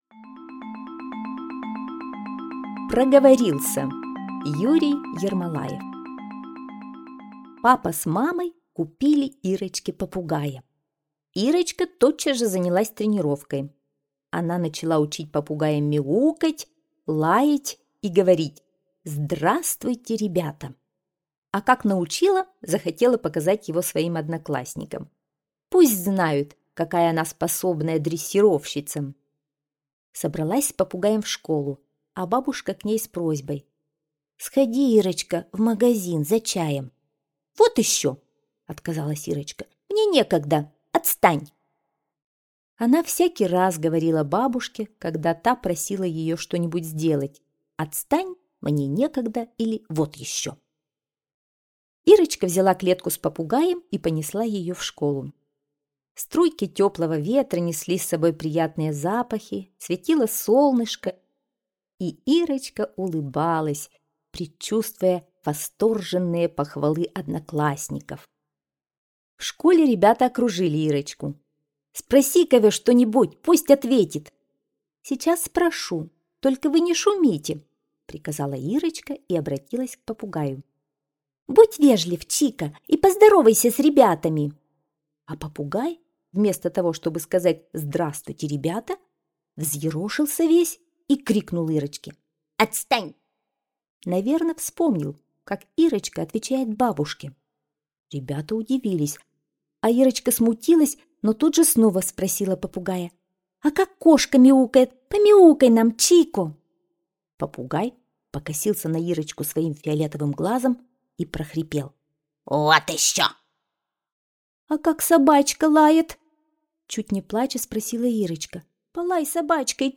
Аудиорассказ «Проговорился»